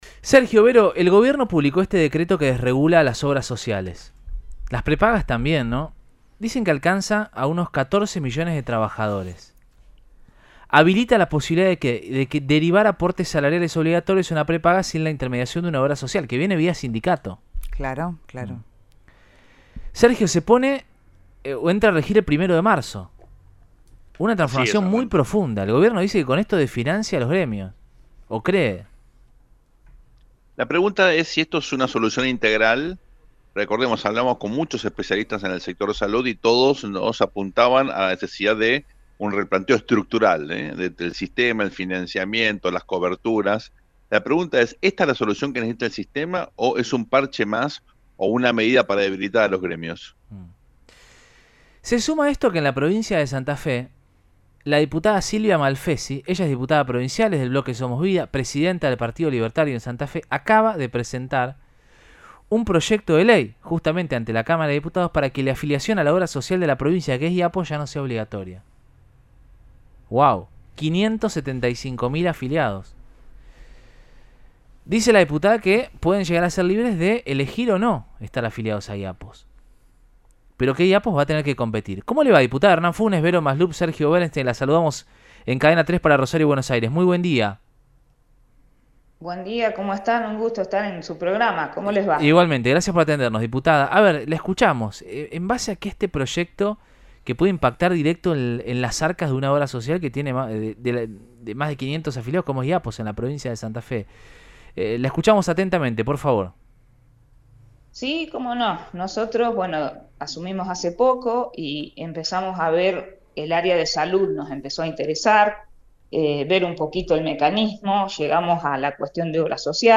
Silvia Malfesi, diputada santafesina y presidenta del Partido Libertario de Santa Fe, destacó en Radioinforme 3, por Cadena 3 Rosario, que el objetivo es “respetar la Constitución Nacional y dar libertad”.